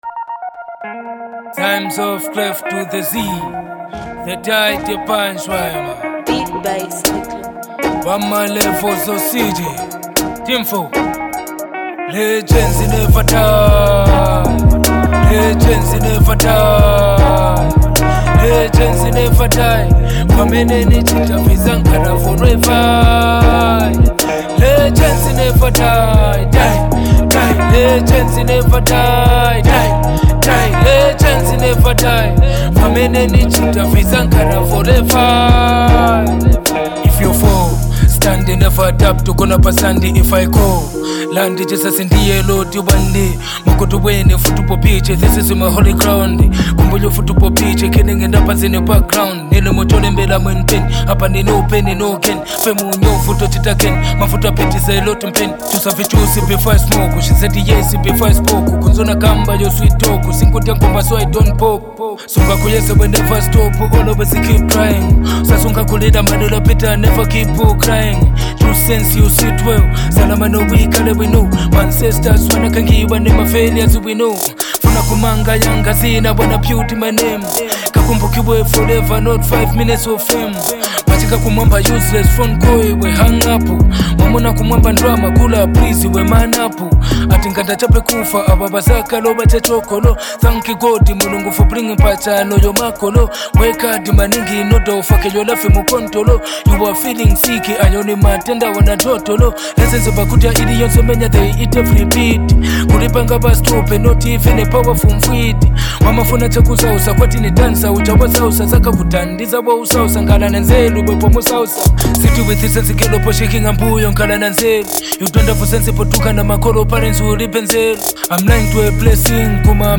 Bold HipHop vibes, catchy beats, and straight fire lyrics.